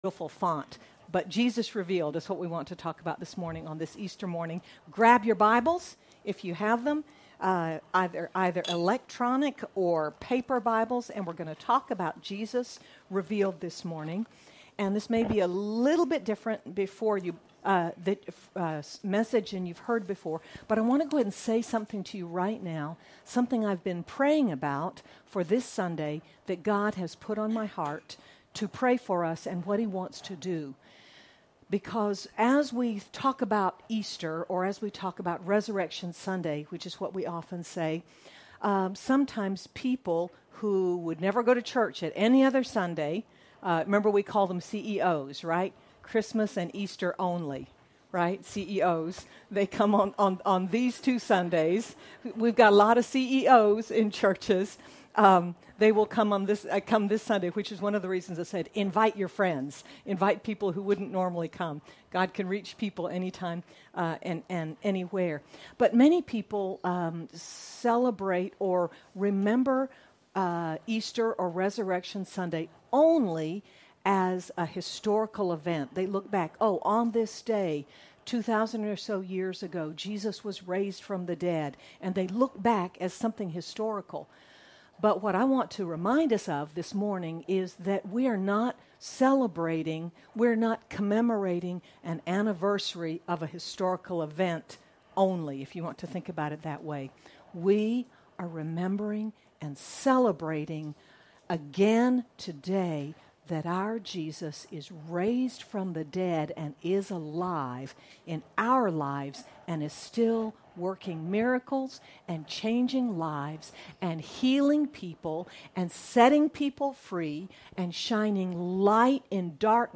Apr 09, 2023 Jesus Revealed MP3 SUBSCRIBE on iTunes(Podcast) Notes Discussion Sermons in this Series Consider how Jesus revealed himself to his followers and disciples after his resurrection—and then open your heart to Jesus and ask him to reveal himself to you again this Easter. Sermon by